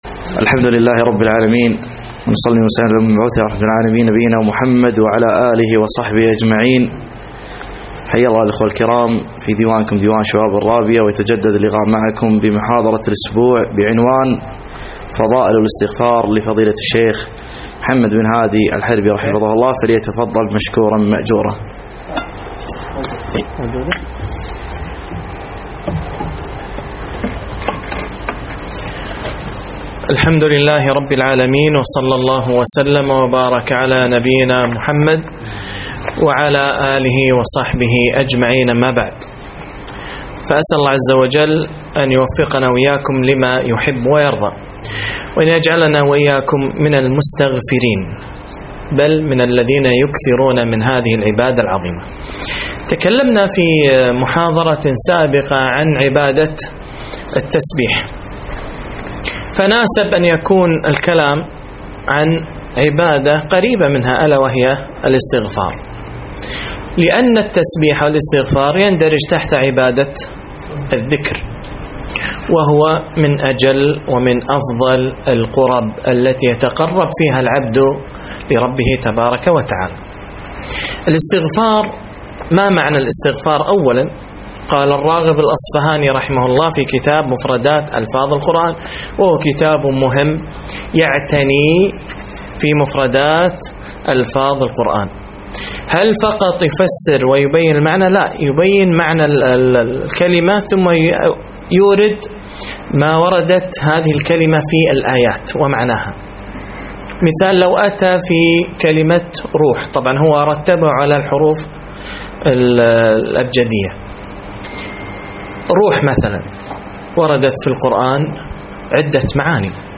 محاضرة - فضائل الاستغفار